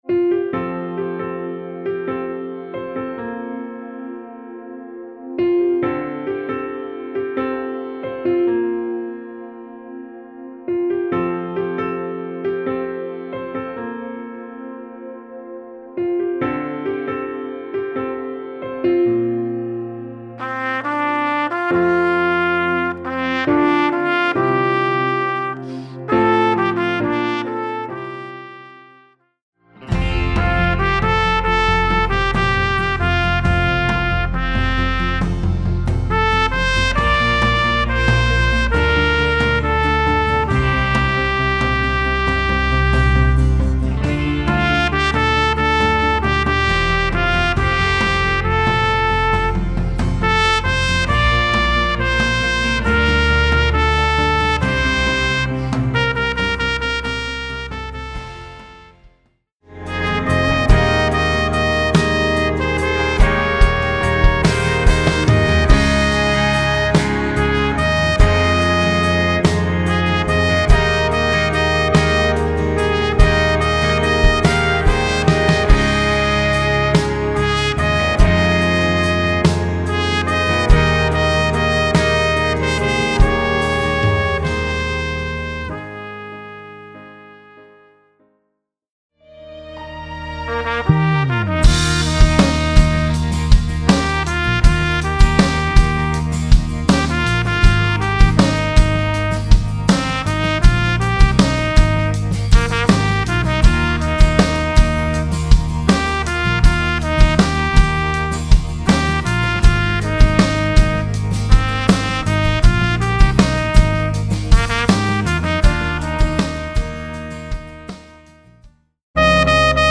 Voicing: Trumpet